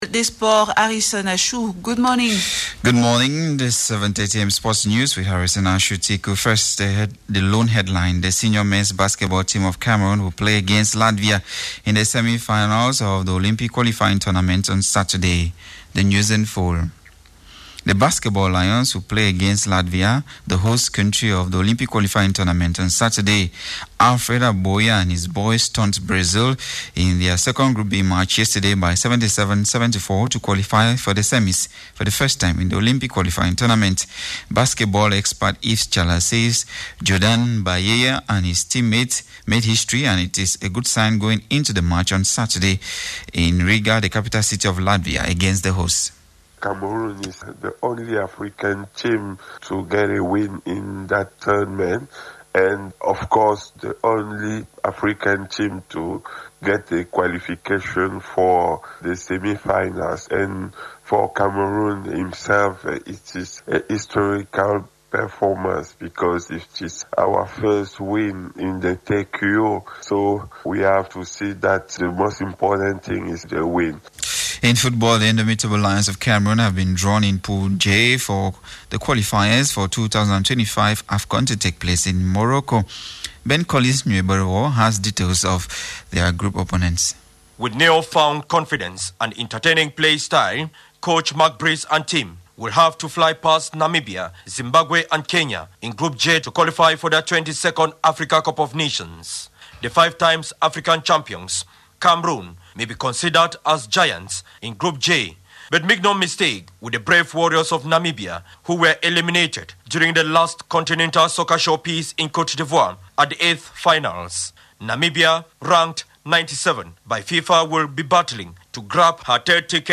The 7:30am_Sports News of July 5 ,2024